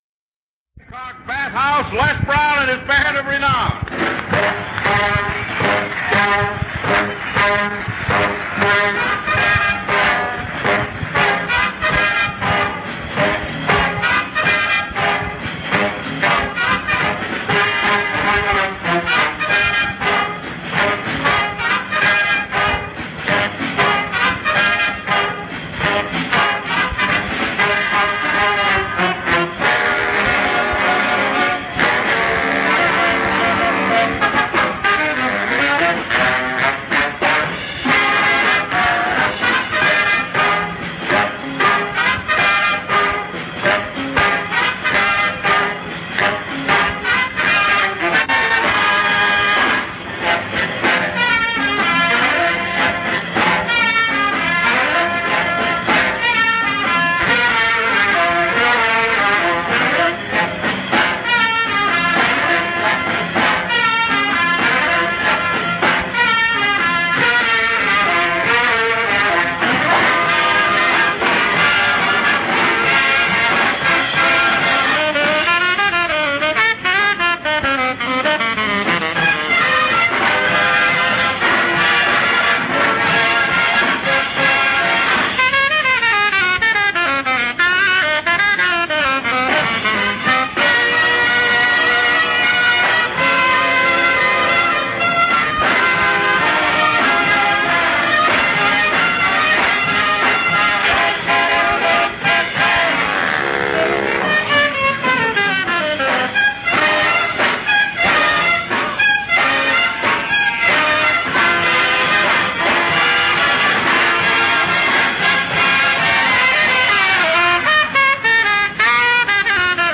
OTR Christmas Shows - Christmas Show Long Binh - 1968-12-xx AFRTS Bob Hope
OTR Radio Christmas Shows Comedy - Drama - Variety.